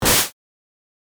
剣撃01 - 音アリー
slashing_01.mp3